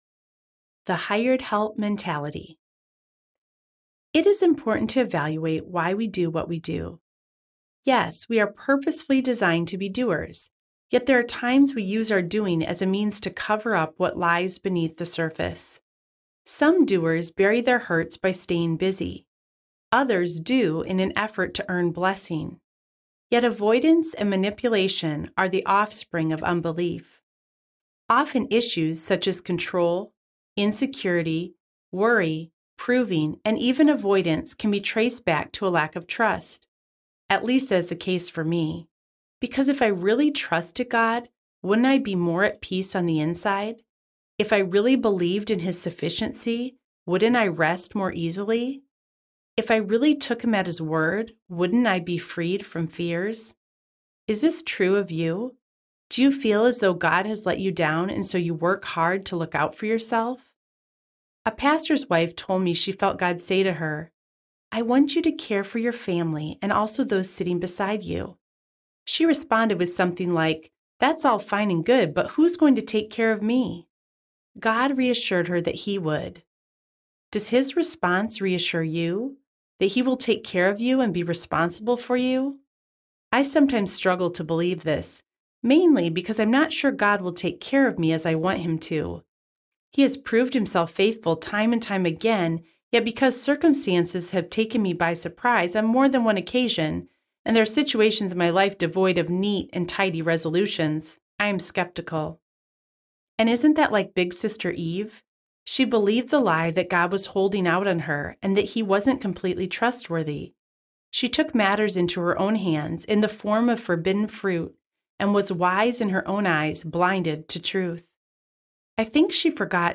Made Like Martha Audiobook
5.55 Hrs. – Unabridged